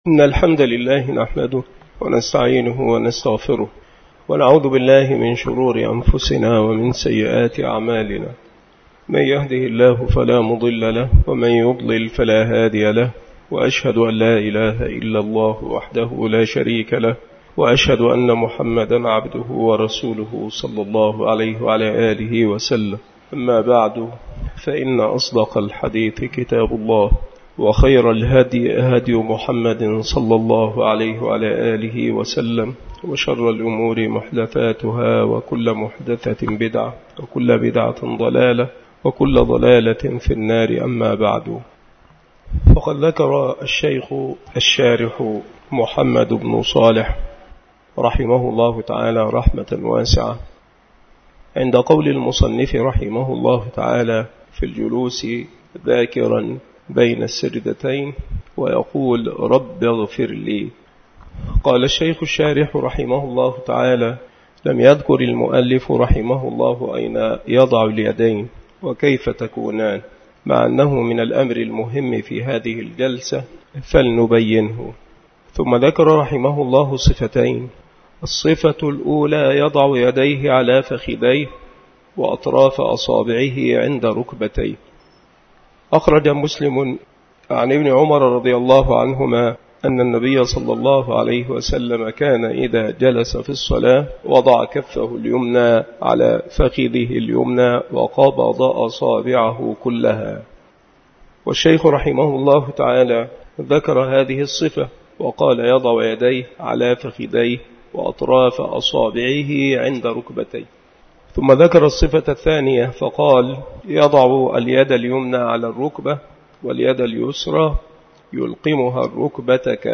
مكان إلقاء هذه المحاضرة بالمسجد الشرقي بسبك الأحد - أشمون - محافظة المنوفية - مصر عناصر المحاضرة : مسألة: وضع اليدين بين السجدتين.